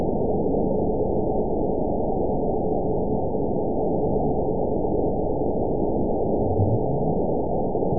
event 921703 date 12/16/24 time 23:15:33 GMT (11 months, 2 weeks ago) score 9.59 location TSS-AB04 detected by nrw target species NRW annotations +NRW Spectrogram: Frequency (kHz) vs. Time (s) audio not available .wav